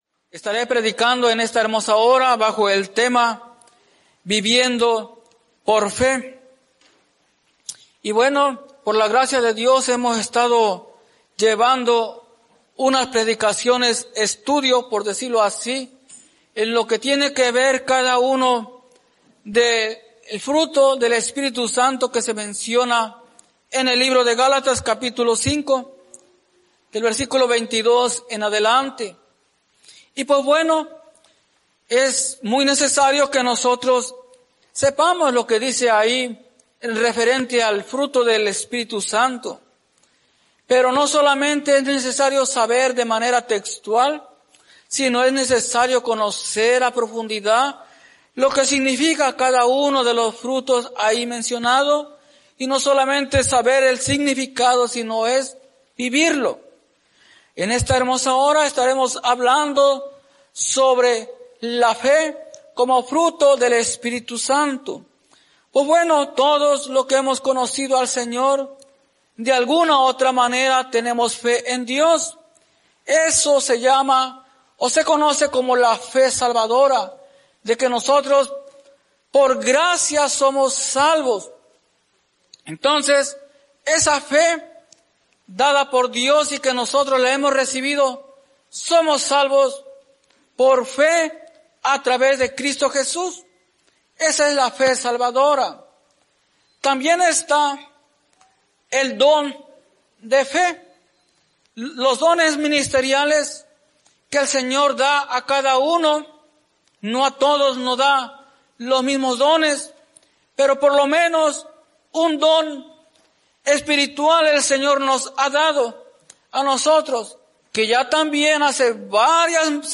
Viviendo por fe Predica